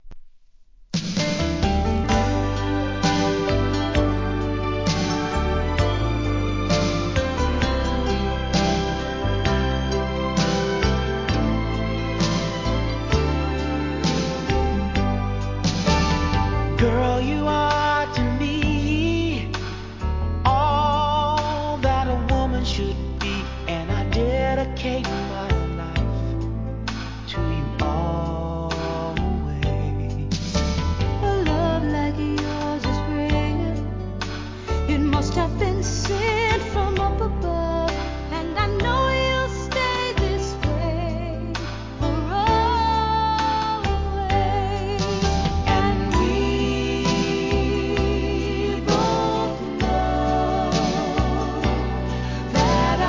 SOUL/FUNK/etc...
1987年、結婚式定番ソング♪